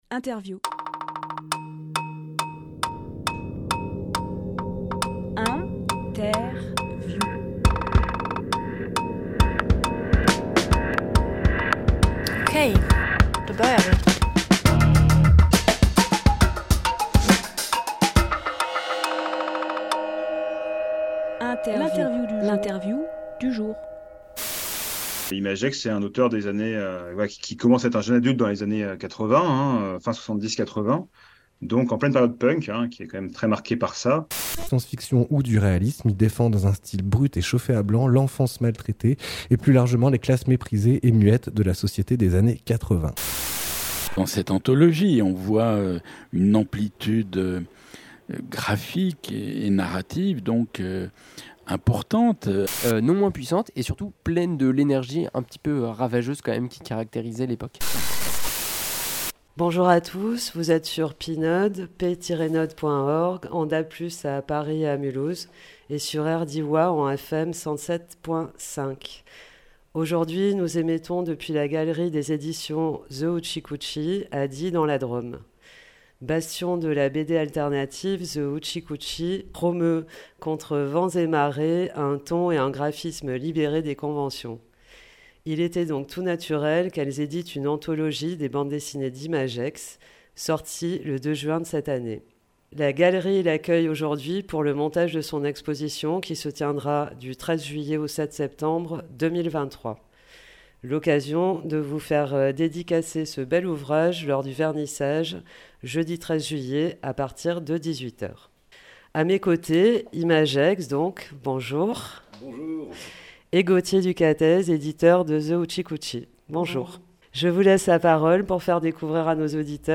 Emission - Interview
Lieu : The Hoochie Coochie